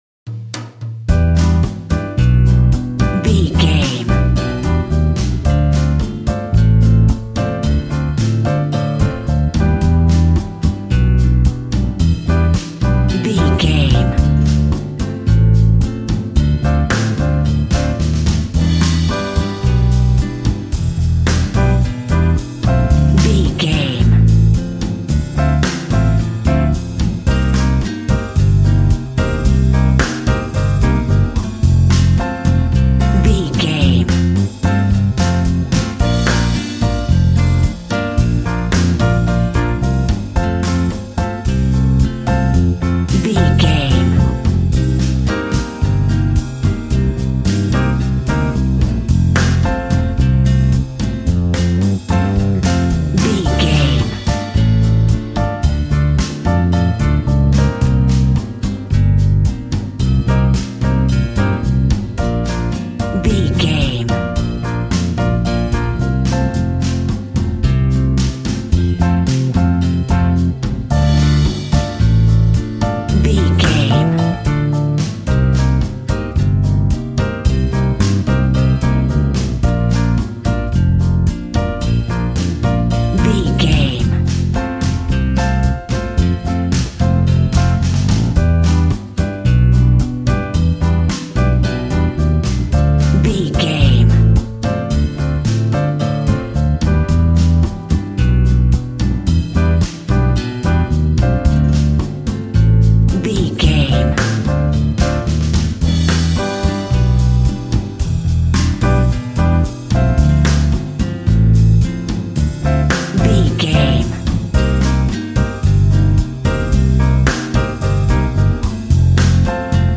Ionian/Major
funky
energetic
romantic
percussion
electric guitar
acoustic guitar